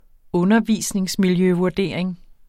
Udtale [ ˈɔnʌˌviˀsneŋsmilˌjøvuɐ̯ˌdeˀɐ̯eŋ ]